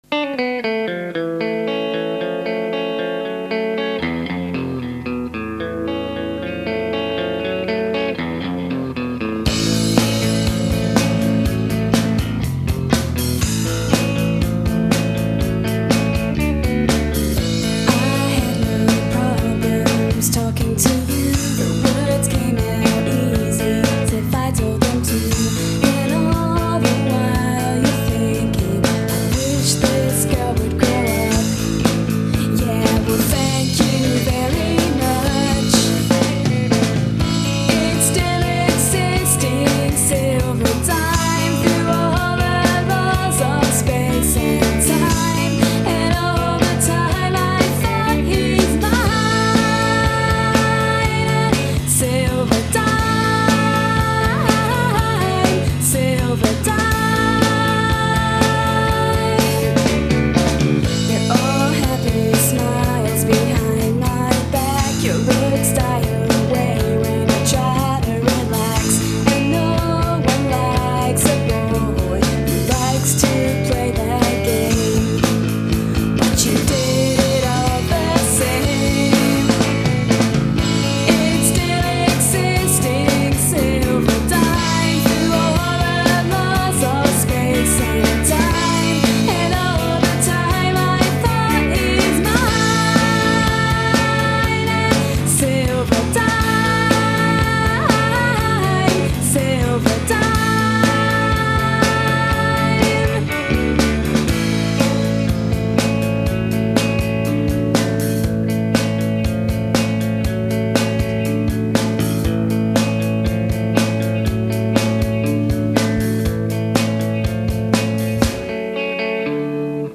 Studio Recordings
Recorded at Chamber Studios, Edinburgh